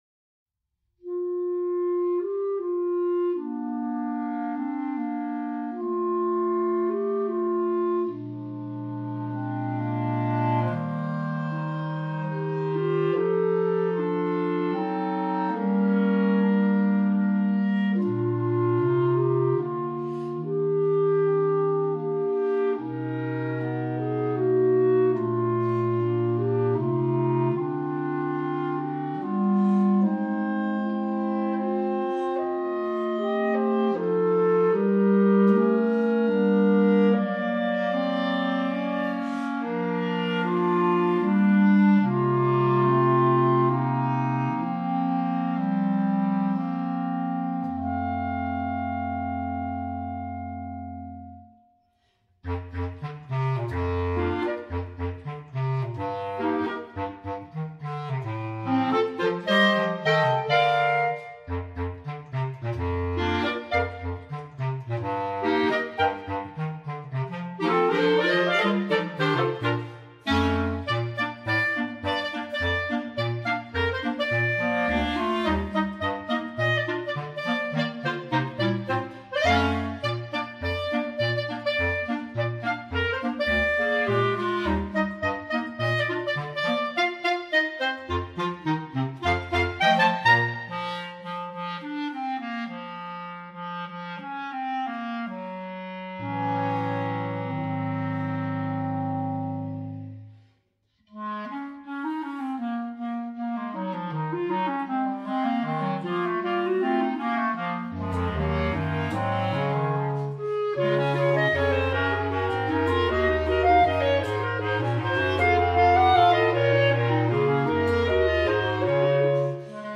B♭ Clarinet 1 B♭ Clarinet 2 B♭ Clarinet 3 Bass Clarinet
单簧管四重奏
圣诞标准曲目串烧。通过突出每首乐曲特色的编曲，既能感受圣诞的欢快氛围，又能品味古典韵味的曲目。